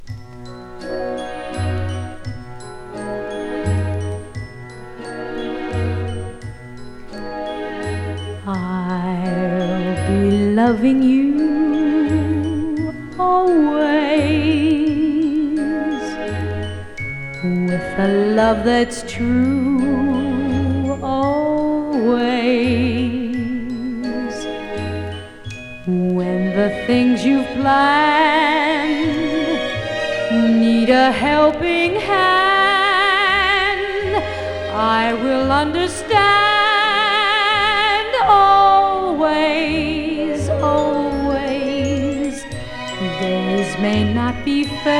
スタンダード楽曲を、伸びやかな歌声がとても良いです。個性としての表現の良さをじっくり感じられるバラード曲も素敵です。
Pop, Vocal, Jazz　USA　12inchレコード　33rpm　Mono